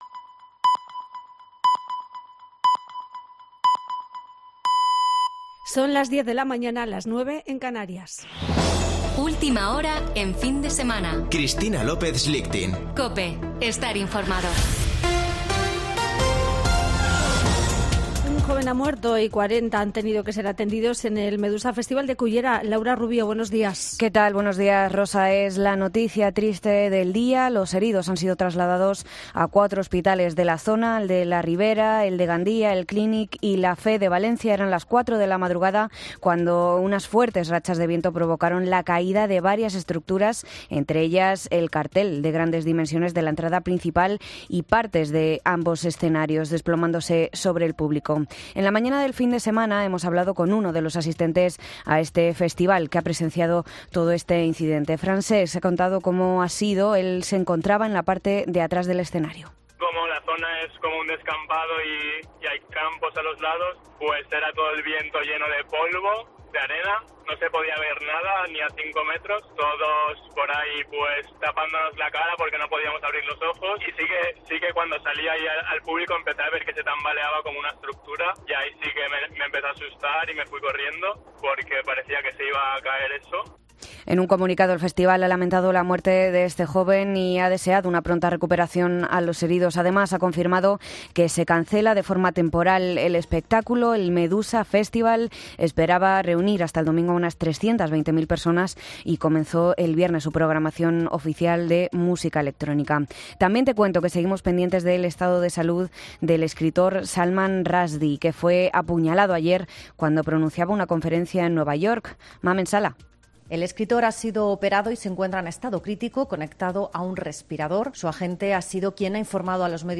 Boletín de noticias de COPE del 13 de agosto de 2022 a las 10.00 horas